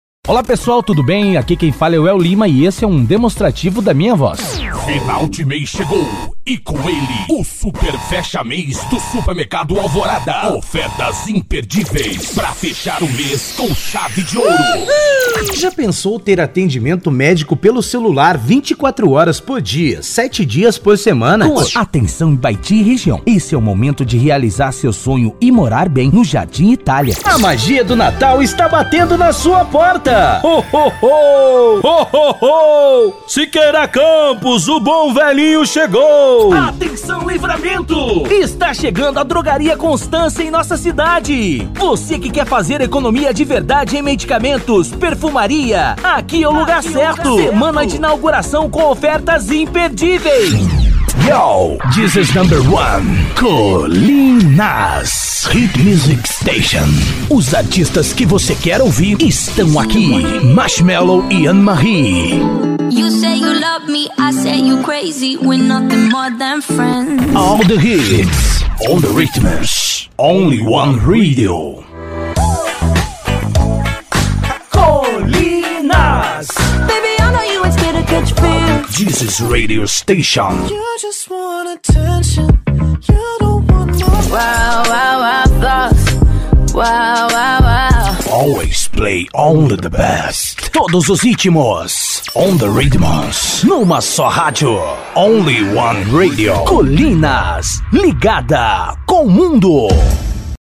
Spot Comercial
Vinhetas
Impacto
Animada
Caricata